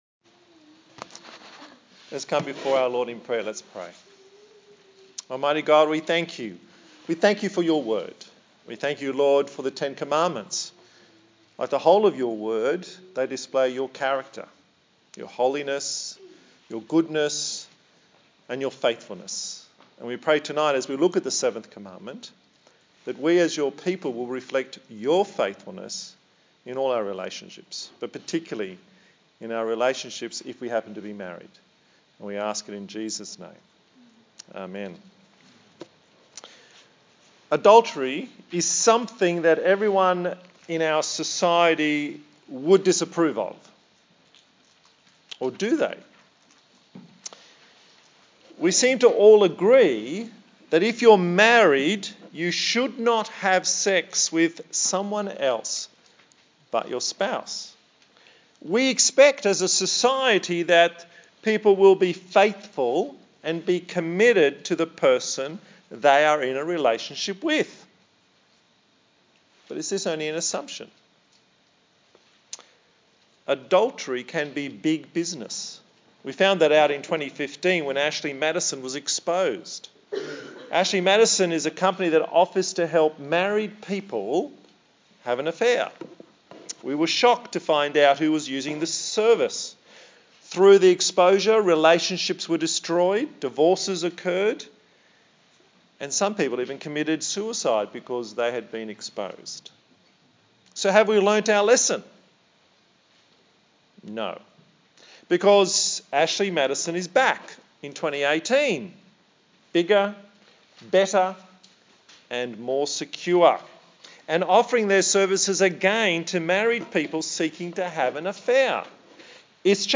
A sermon in the series on The Ten Commandments